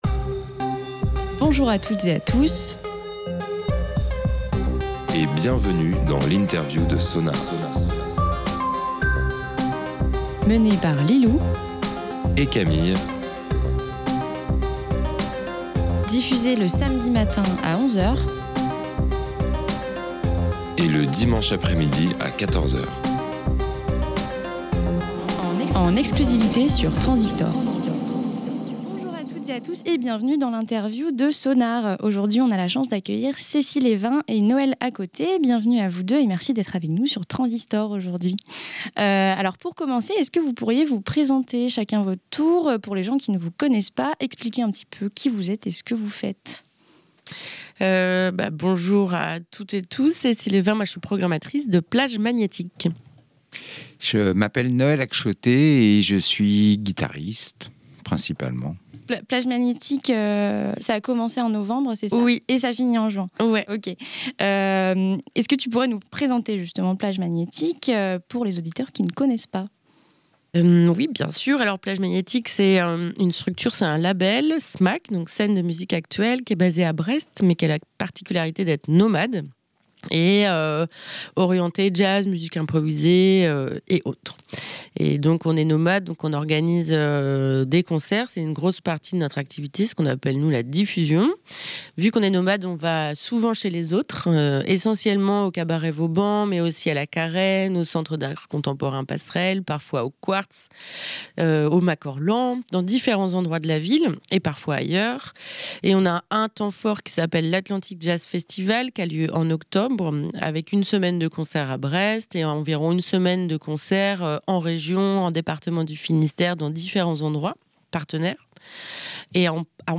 SONAR x INTERVIEW "Plages Magnétiques"